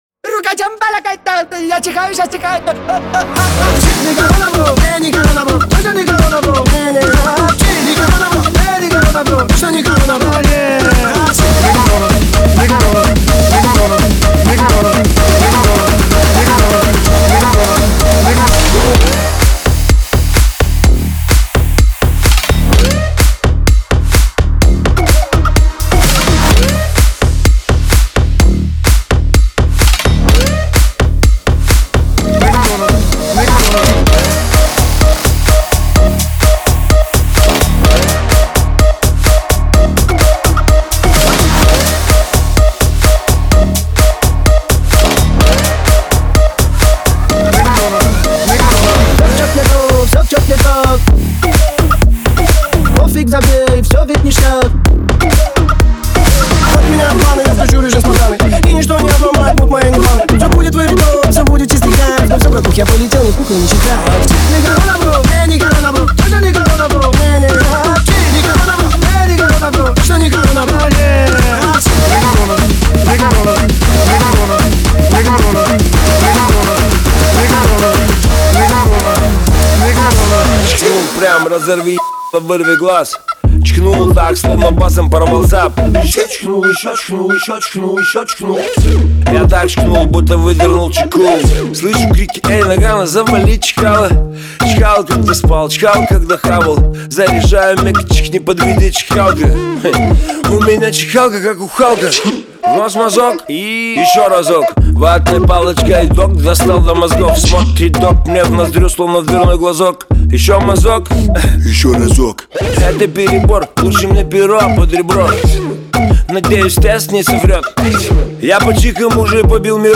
это зажигательный трек в жанре хип-хоп с элементами trap.